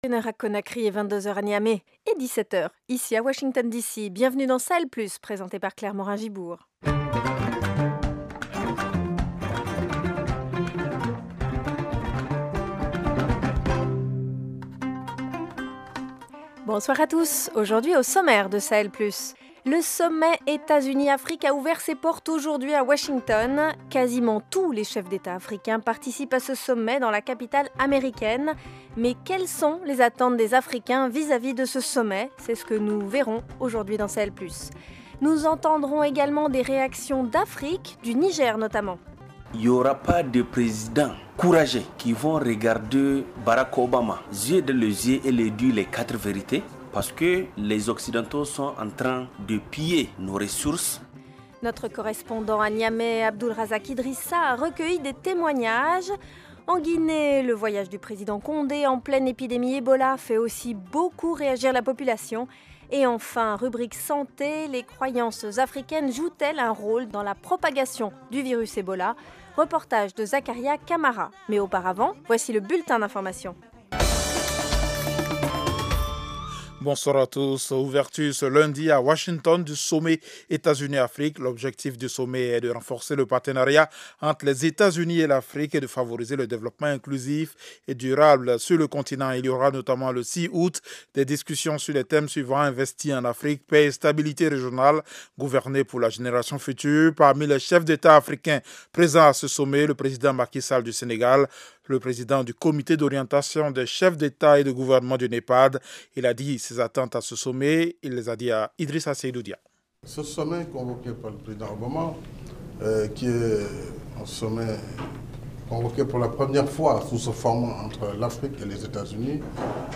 En Guinée, le voyage du président Condé en pleine épidémie Ebola fait aussi beaucoup réagir la population. Rubrique Santé : les croyances traditionnelles africaines jouent-elles un rôle dans la propagation du virus Ebola ? Reportage